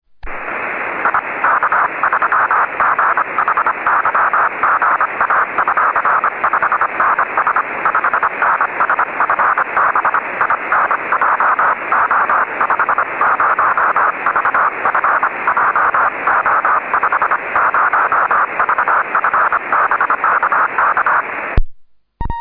REGISTRAZIONI DEGLI ECHI LUNARI DEI BEACONS E DEI QSO